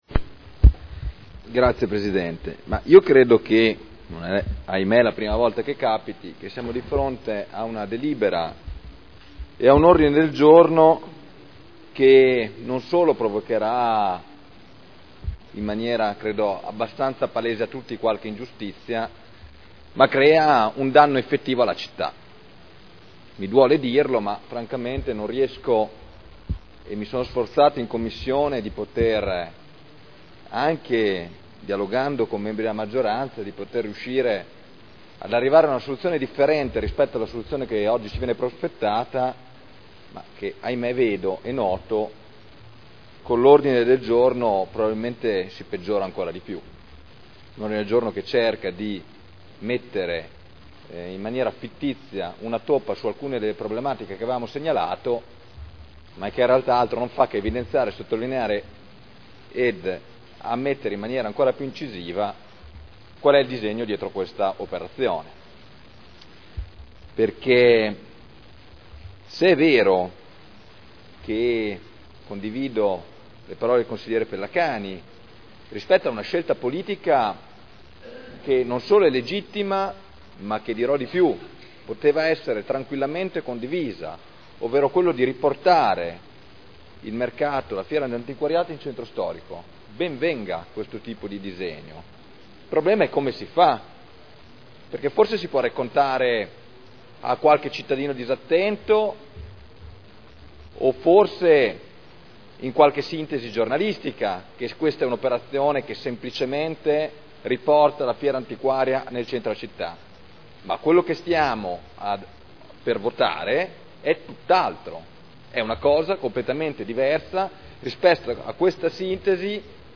Seduta del 22/12/2011. Dibattito su nuovo ordine del giorno e proposta di deliberazione.